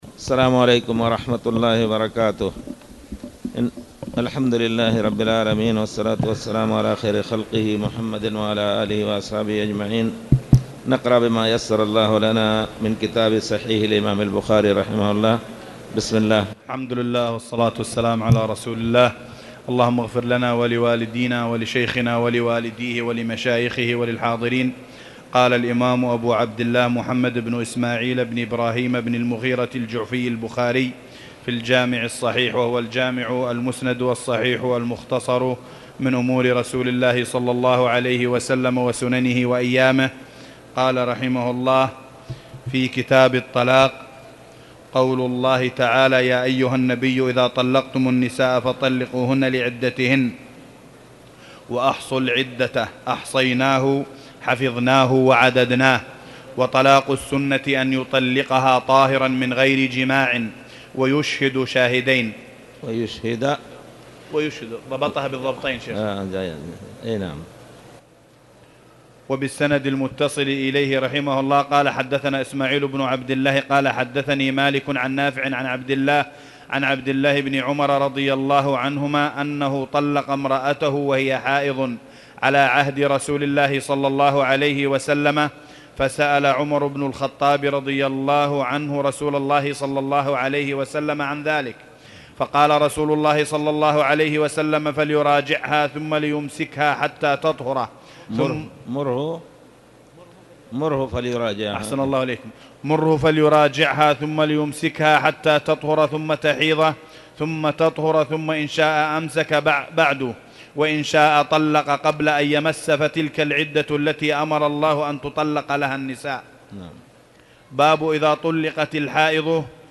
تاريخ النشر ١١ ربيع الأول ١٤٣٨ هـ المكان: المسجد الحرام الشيخ